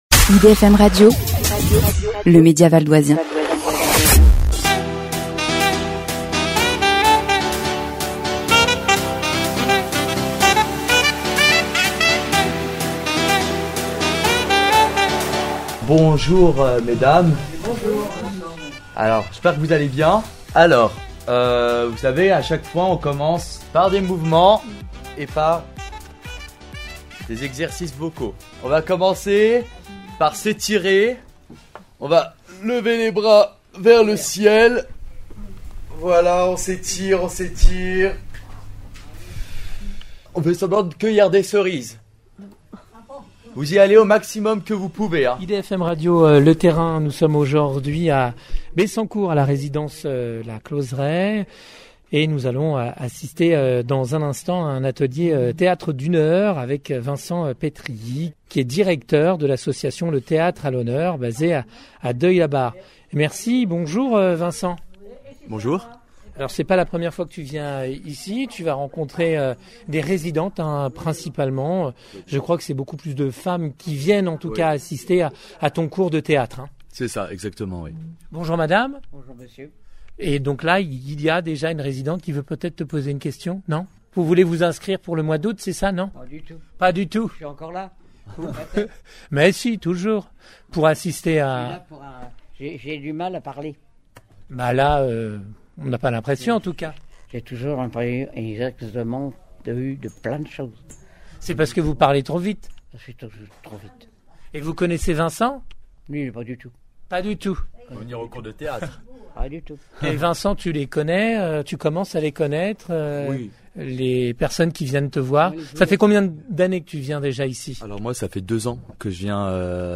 Nous sommes avec lui à Bessancourt dans une résidence pour personnes âgées à la découverte des activités culturelles axées sur le théâtre.
Le terrain avec votre radio Val d’Oisienne :